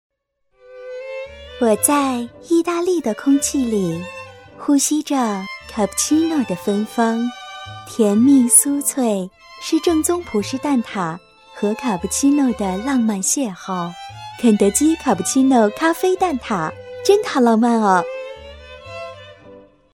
配音风格： 甜美 自然